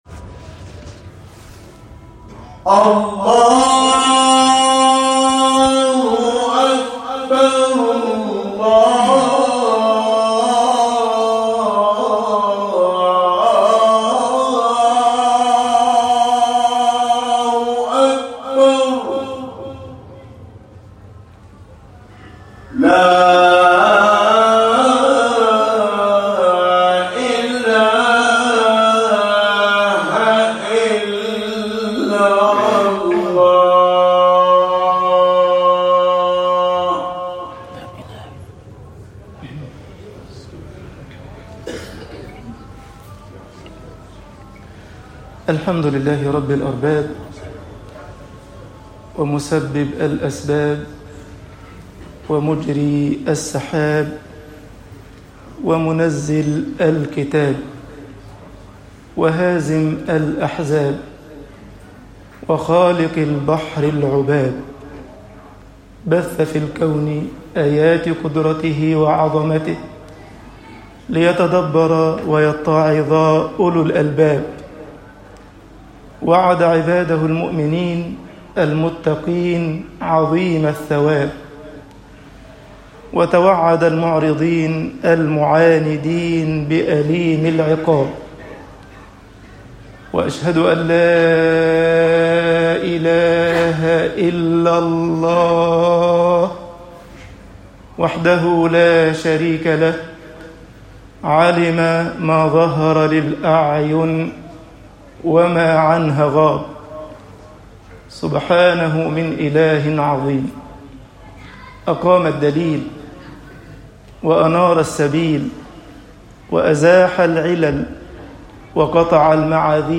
خطب الجمعة - مصر